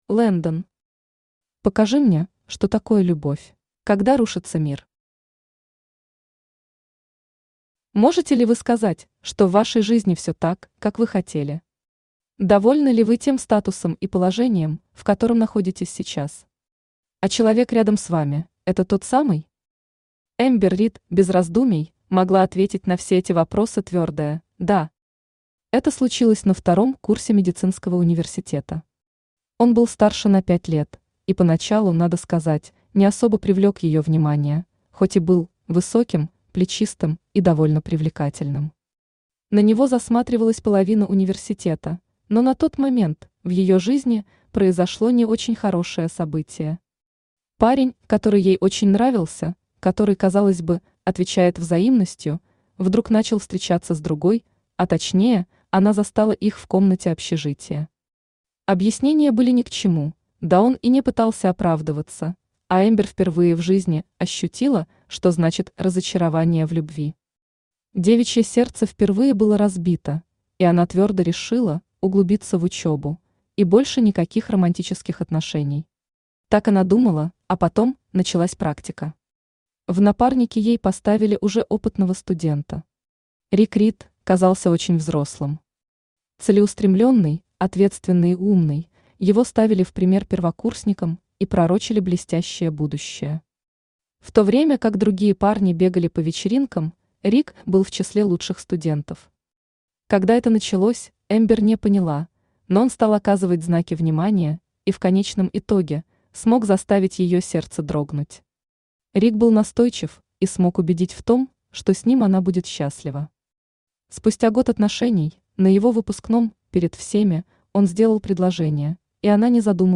Аудиокнига Покажи мне, что такое любовь | Библиотека аудиокниг
Aудиокнига Покажи мне, что такое любовь Автор Landen Читает аудиокнигу Авточтец ЛитРес.